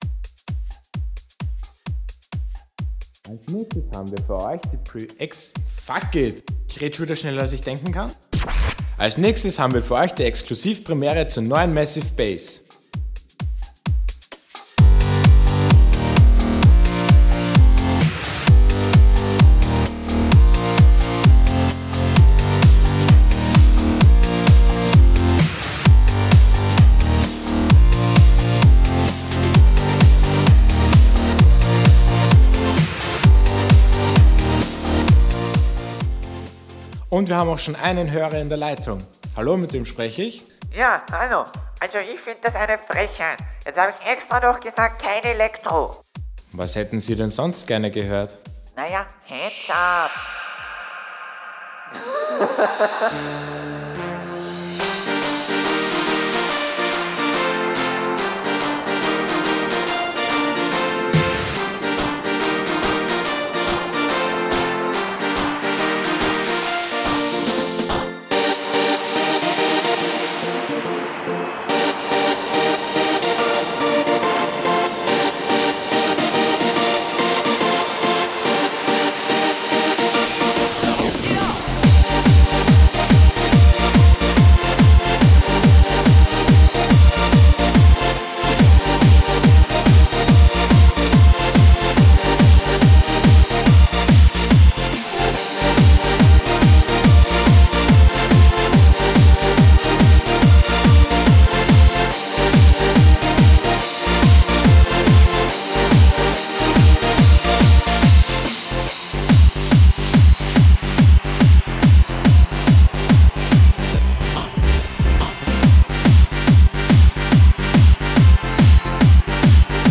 PS: German Vocals inside